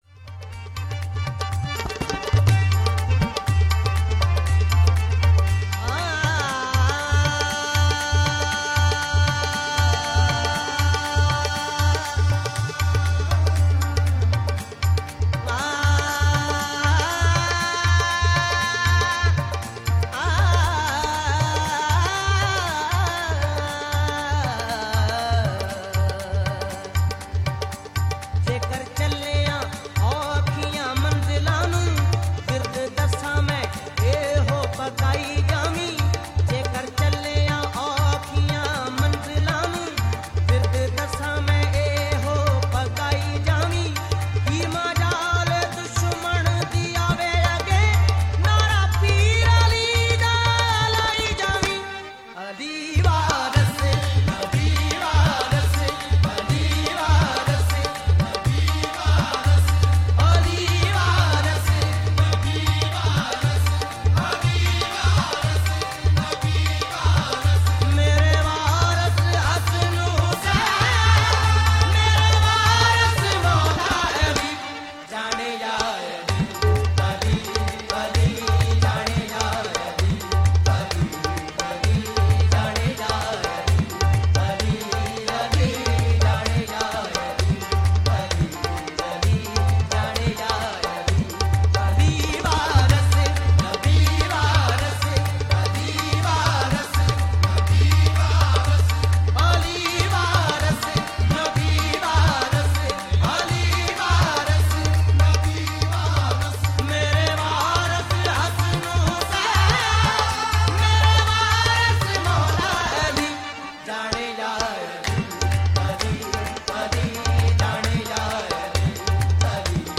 Punjabi Qawwali and Sufiana Kalam